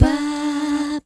Note 3-D.wav